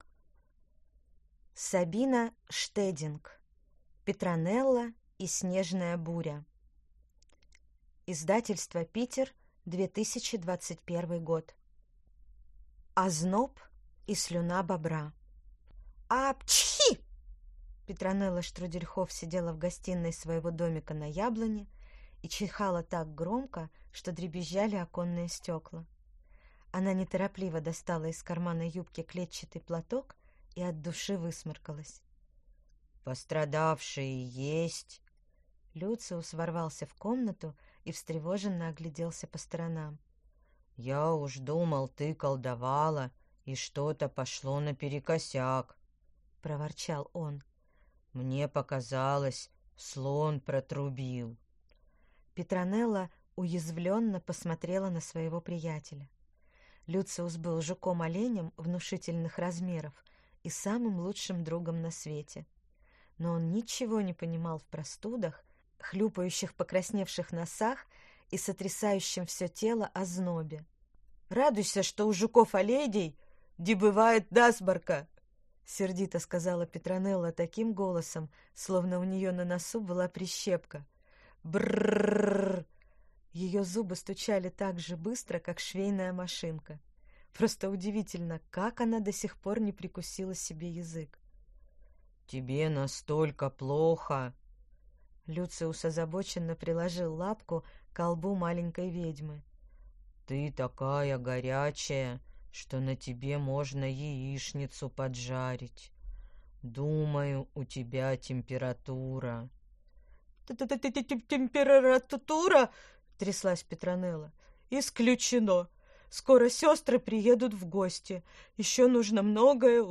Аудиокнига Петронелла и снежная буря | Библиотека аудиокниг